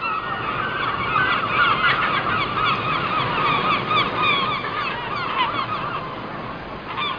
gulls1.mp3